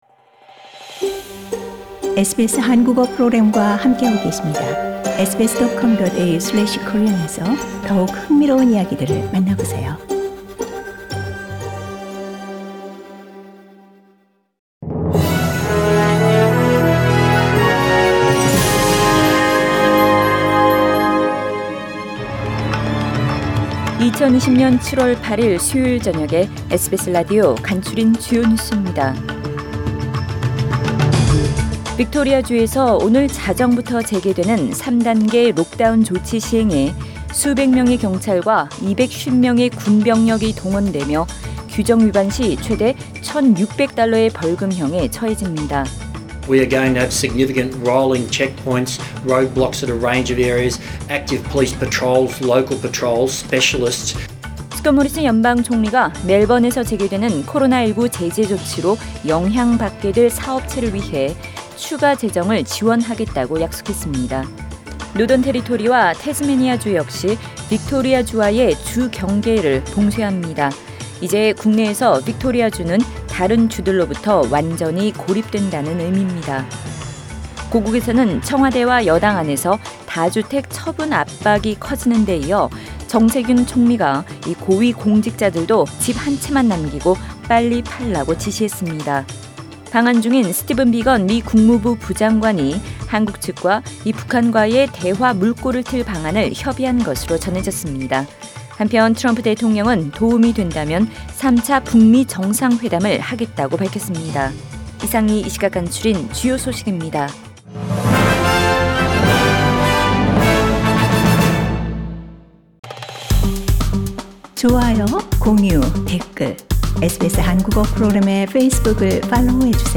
2020년 7월 8일 수요일 저녁의 SBS Radio 한국어 뉴스 간추린 주요 소식을 팟 캐스트를 통해 접하시기 바랍니다.